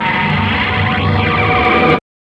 5006L SYNTH.wav